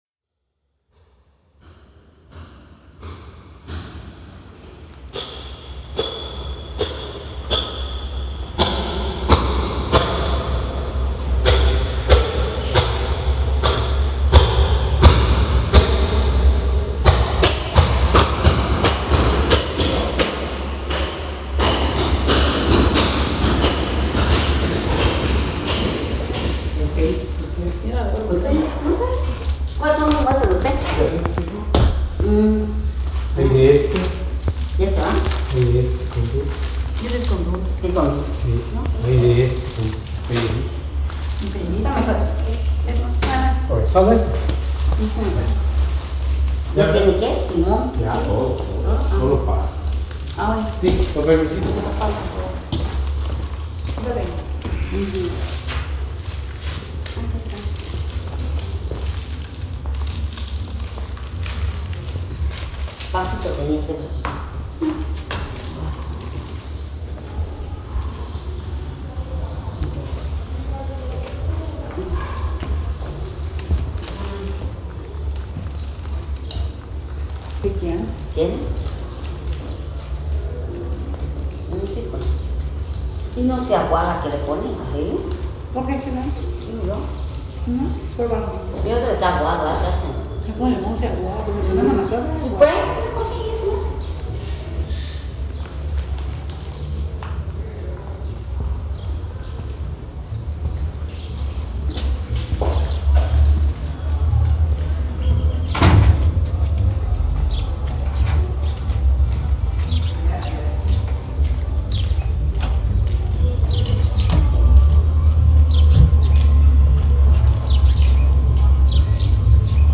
Recorrido sonoro lugares en Chiapa de Corzo